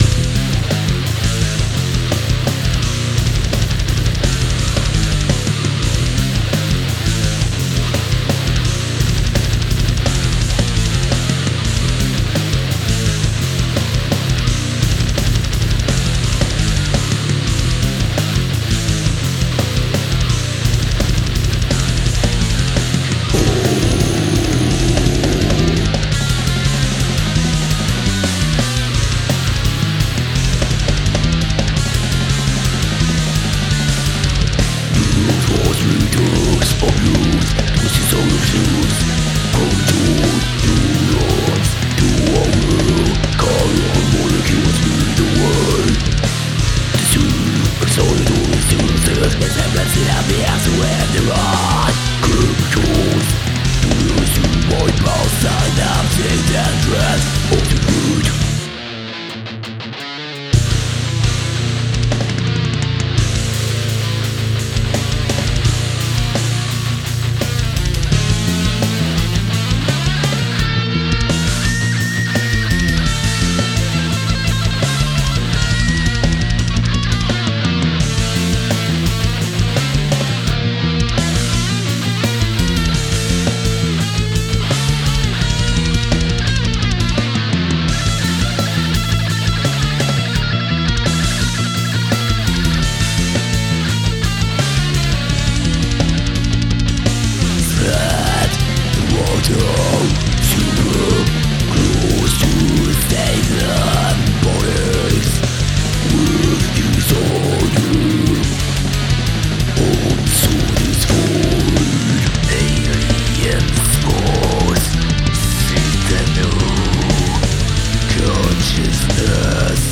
...is the name of my one-man progressive death metal project.